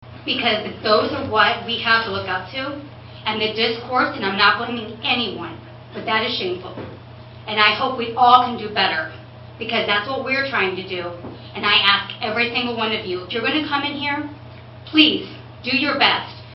Tensions rose between the public during Wednesday’s USD 383 Manhattan-Ogden school board meeting.
Board Member Brandi Santos says while she was disappointed and angry at what happened, her main concern was that there were no students in the audience when the incident went down.